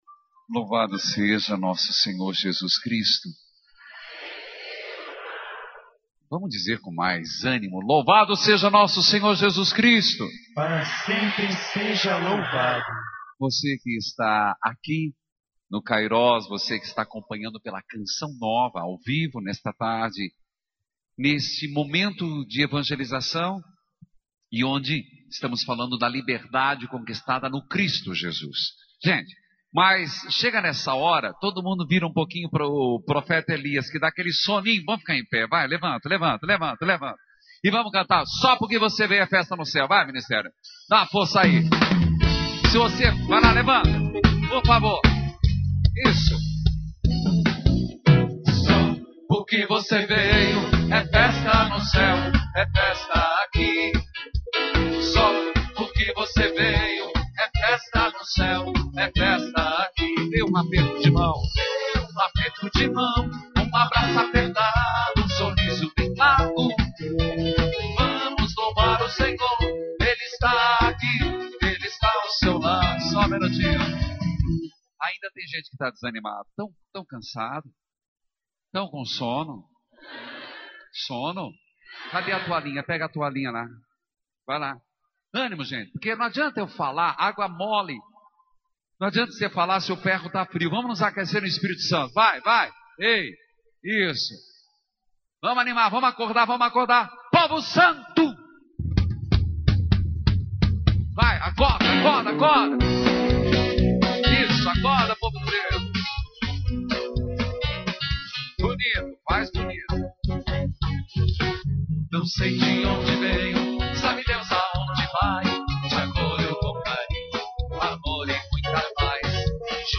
Palestra com o padre Reginaldo Manzotti